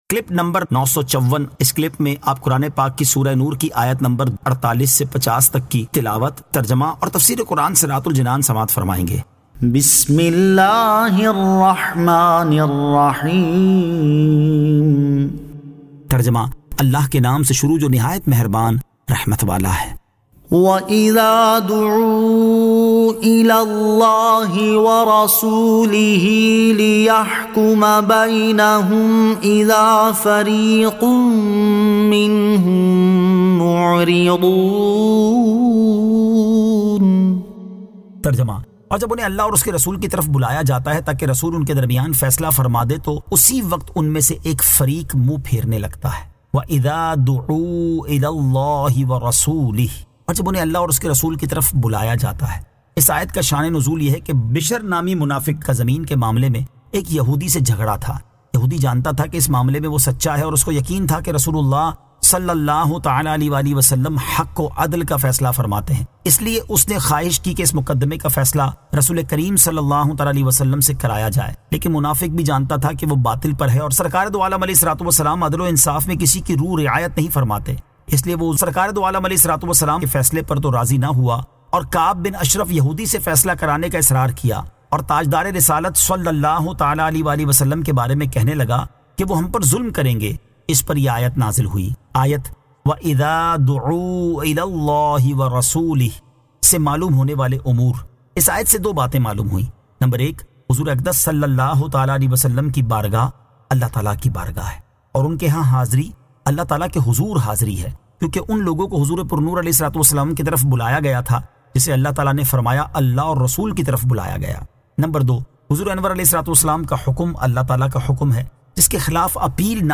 Surah An-Nur 48 To 50 Tilawat , Tarjama , Tafseer